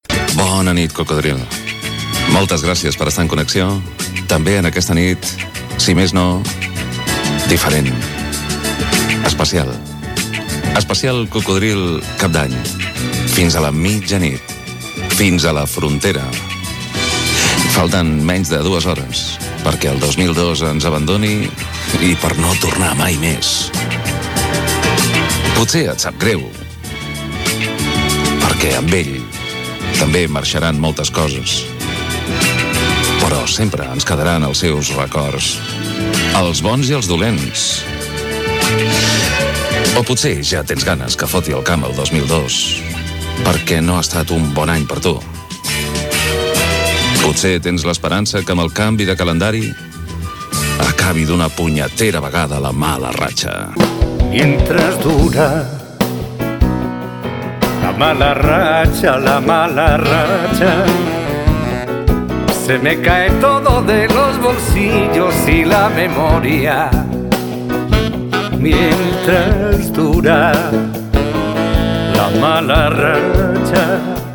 Fragment de l'especial cap d'any 2002 de "Cocodril Club", reflexions sobre el canvi de l'any i tema musical.
Musical